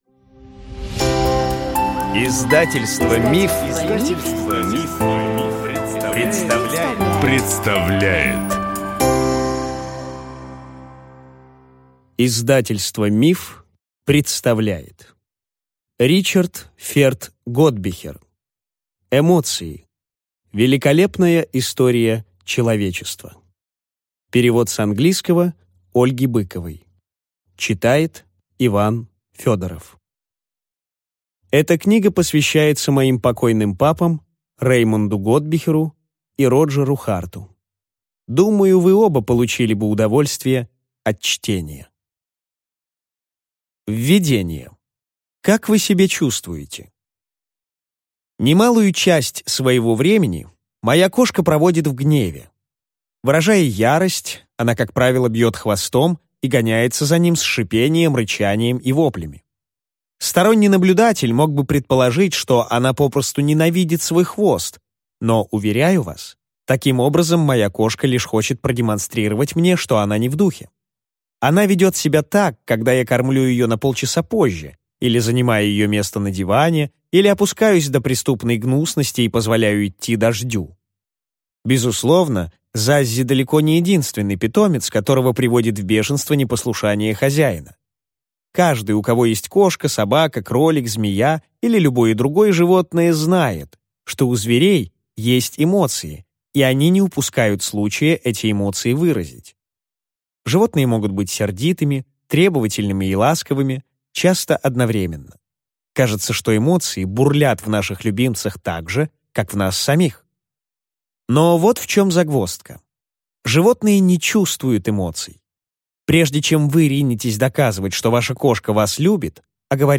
Аудиокнига Эмоции: великолепная история человечества | Библиотека аудиокниг